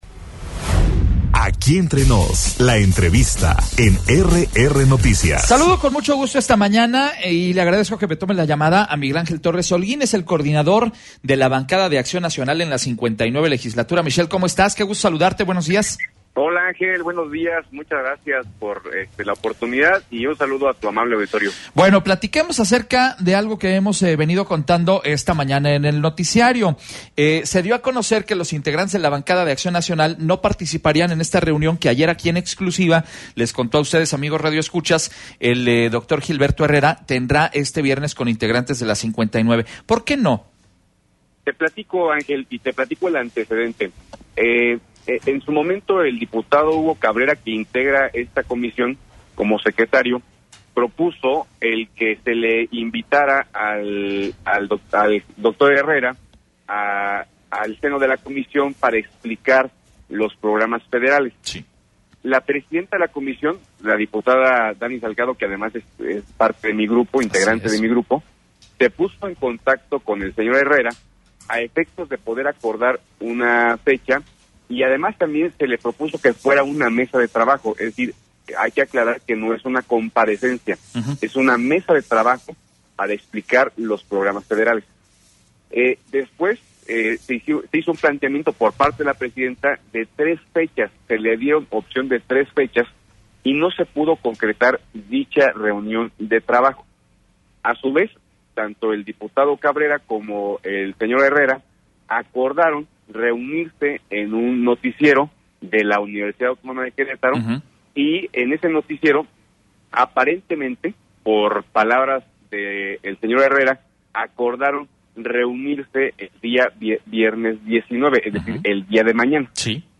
ENTREVISTA-DIP-MIGUEL-ANGEL-TORRES.mp3